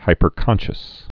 (hīpər-kŏnshəs)